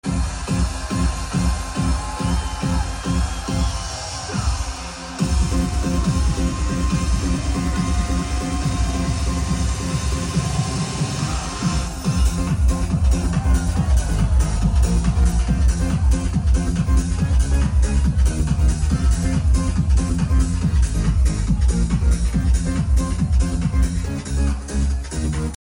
Banging set